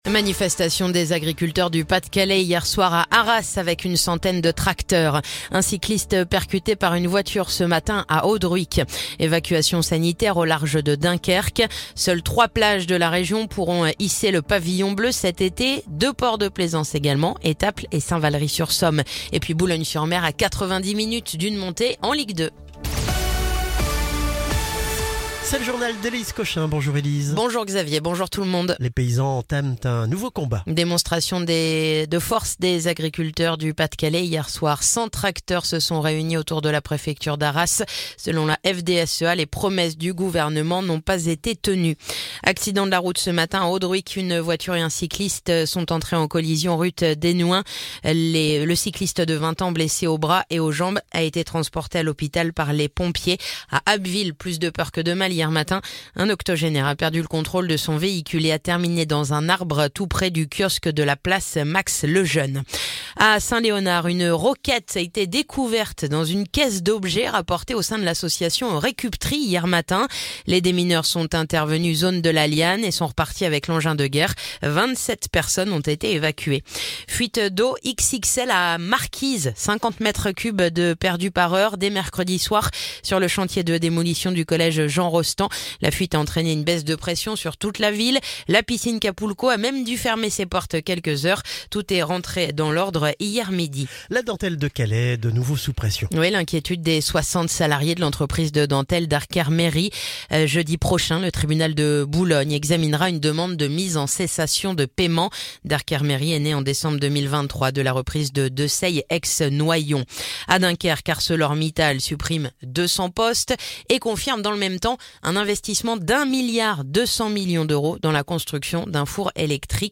le joural du vendredi 16 mai